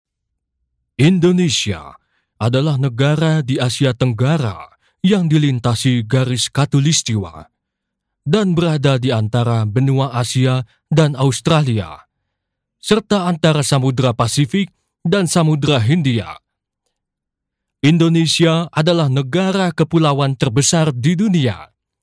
Indonesian voice over